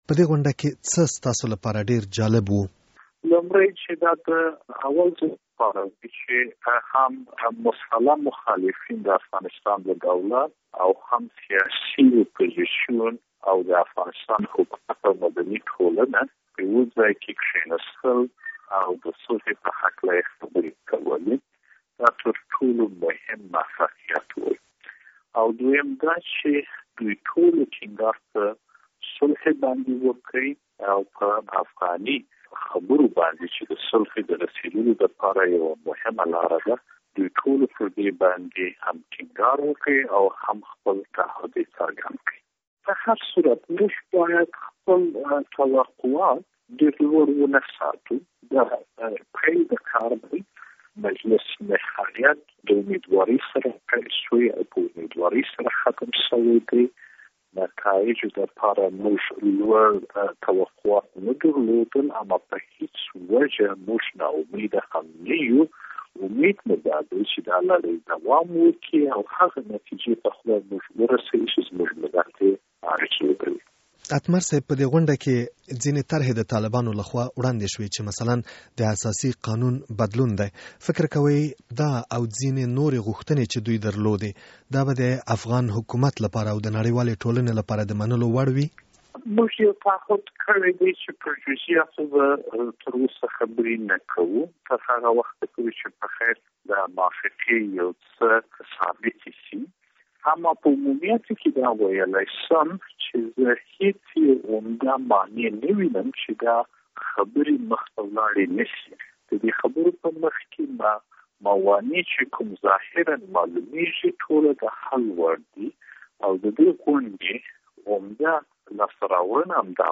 له حنیف اتمر سره مرکه